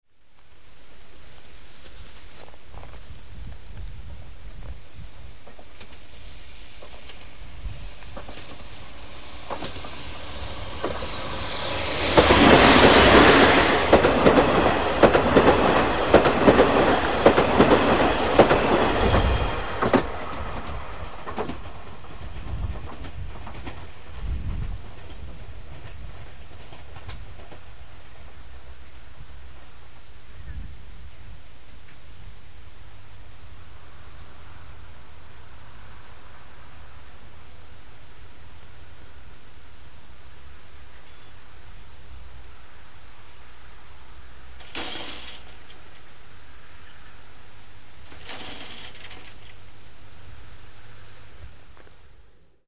関西線　関を通過するＤＤ５１牽引の修学旅行列車（１）　昭和56年11月
（通過音）ＭＰ3ステレオ　　823ＫＢ　52秒
加太から下ってきた臨時列車。オハ５０系が軽やかに走り抜けていきます。最後に腕木信号が上がる音。０８・１０・２５